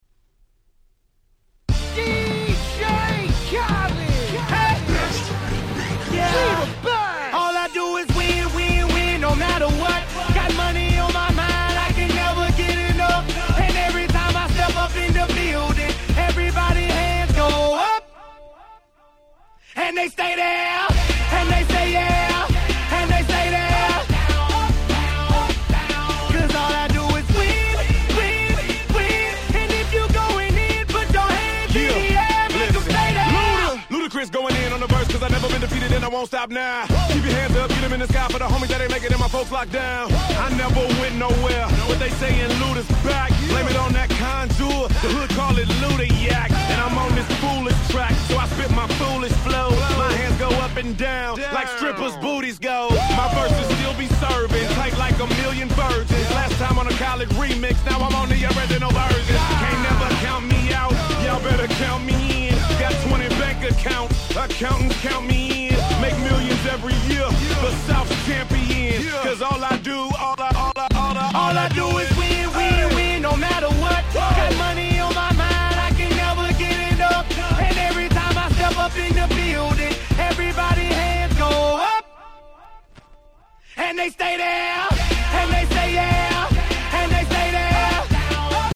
White Press Only EDM/Hip Hop Remixes !!